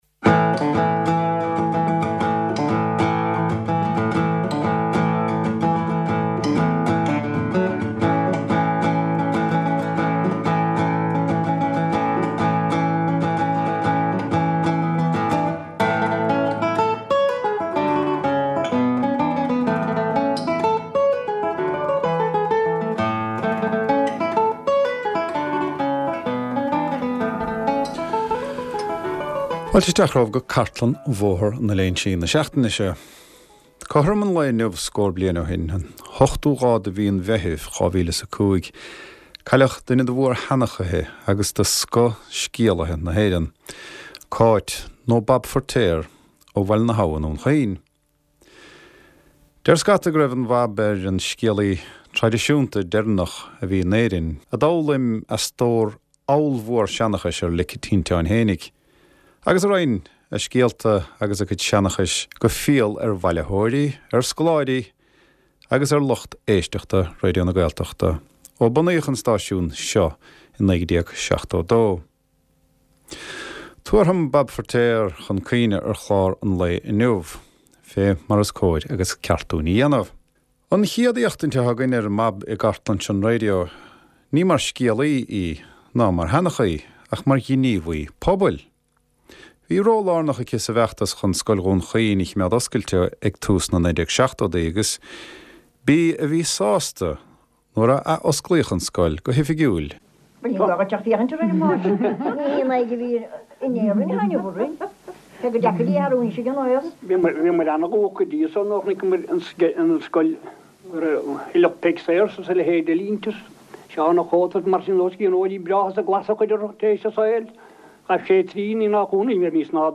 Glórtha ó chartlann Bhóthar na Léinsí - scéalta agus seanchas, amhráin agus ceol, stair agus sochaí na Gaeltachta. / Voices from RTÉ Raidió na Gaeltachta's Baile na nGall archive, including stories and folklore, songs and music, history and descriptions of the Gaeltacht community.